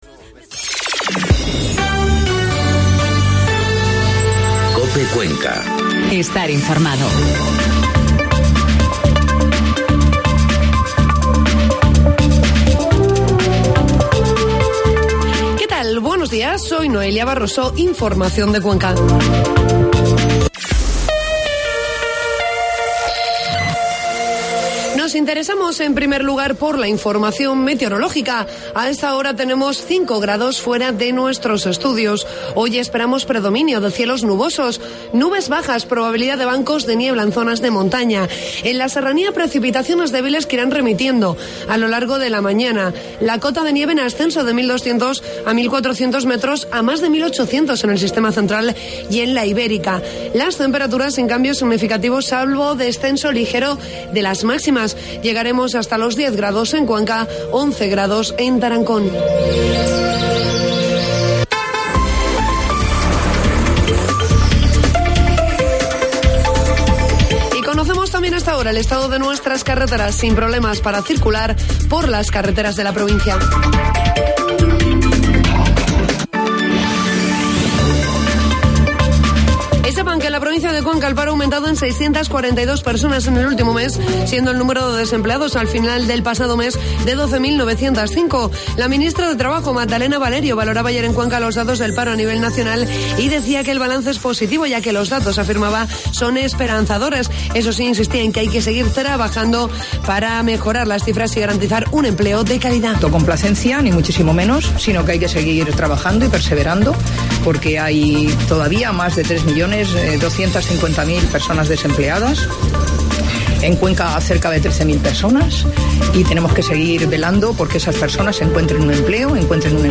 Informativo matinal COPE Cuenca 6 de noviembre